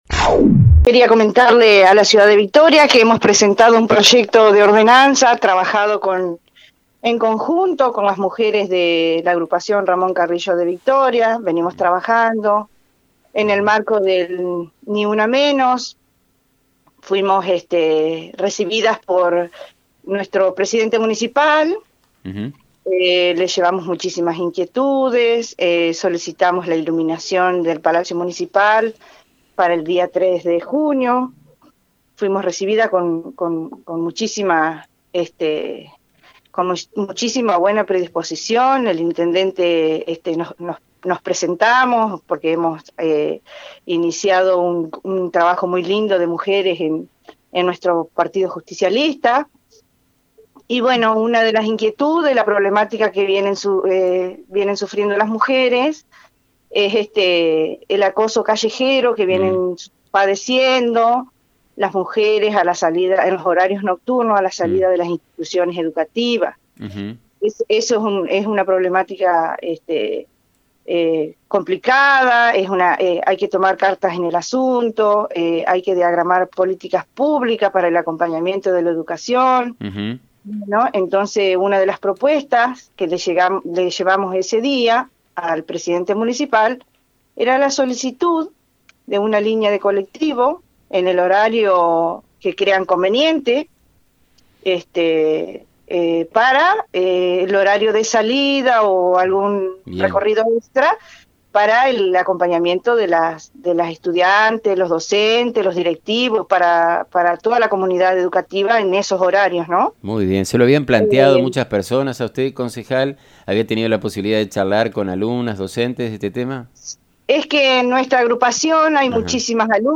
En diálogo con FM 90.3, la concejal Carolina Delgado declaró que en la sesión del Concejo Deliberante se tratará un proyecto de ordenanza para que el Transporte Urbano Municipal circule de noche y se modifique su circuito.